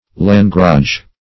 Search Result for " langrage" : The Collaborative International Dictionary of English v.0.48: Langrage \Lan"grage\, Langrel \Lan"grel\, n. A kind of shot formerly used at sea for tearing sails and rigging.